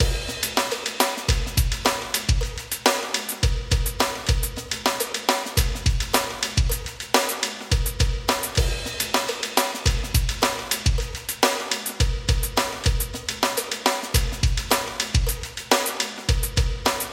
斩钉截铁的桑巴舞
描述：用Roland VDrum TD25演奏和录音。没有任何东西被量化。
Tag: 105 bpm Samba Loops Drum Loops 2.88 MB wav Key : Unknown